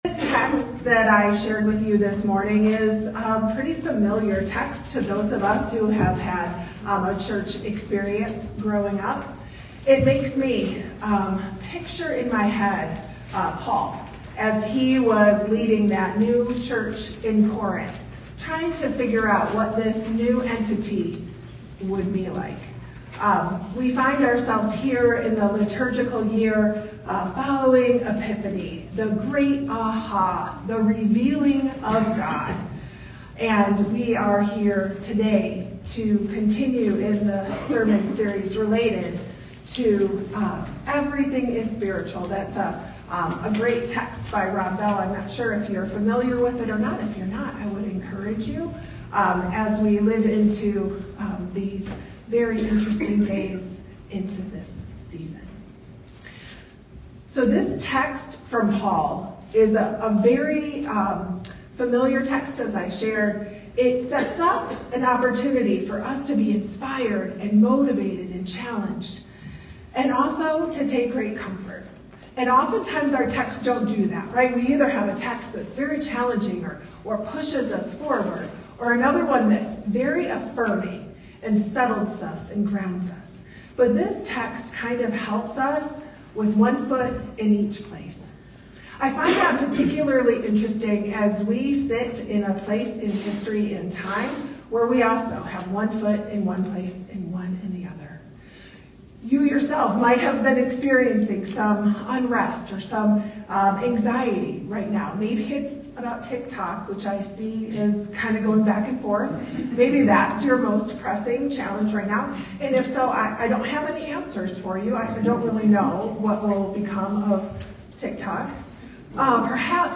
Sermons | Covenant Presbyterian DTC